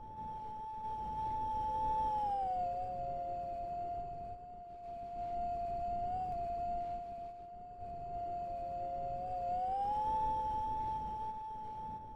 moan4.ogg